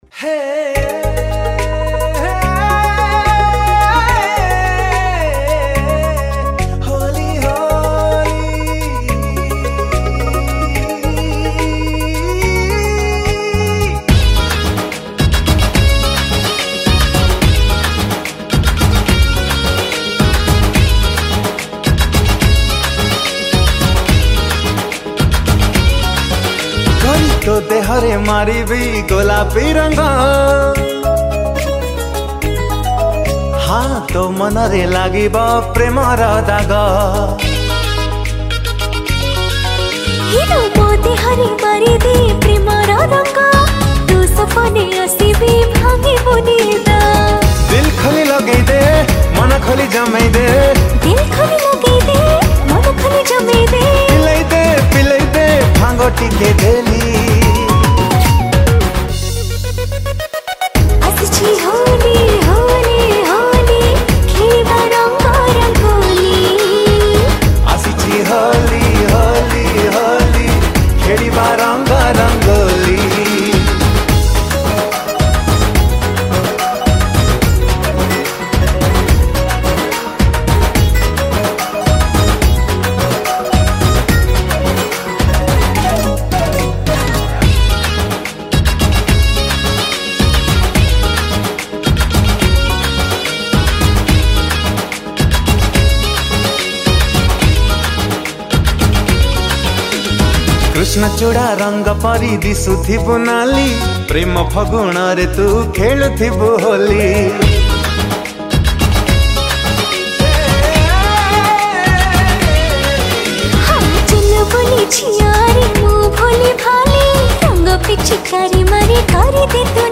Holi Spcial Mp3 Song Songs Download
Keyboard